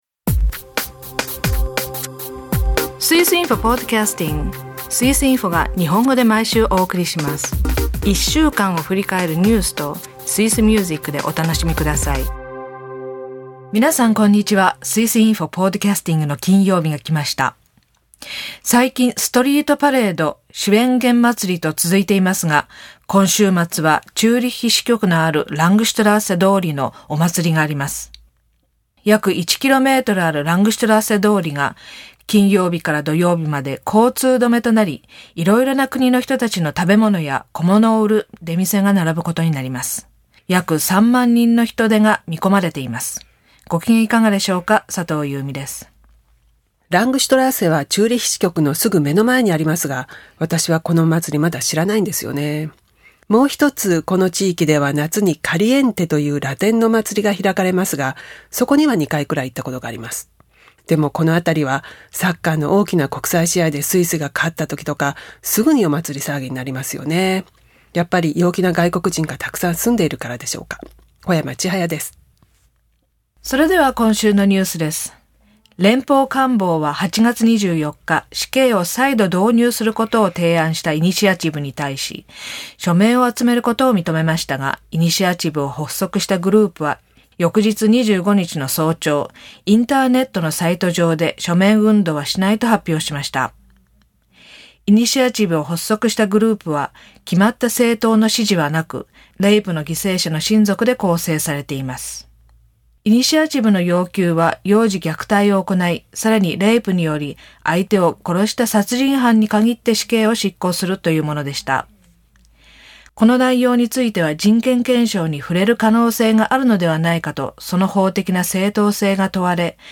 8月最後のポッドキャストは、死刑再導入を求めるイニシアチブ、署名活動に入る前に撤回。曲はアンニュイな「カンパリソーダ」をどうぞ。朗読「スイス傭兵ブレーカーの自伝」では、いよいよ出陣となりました。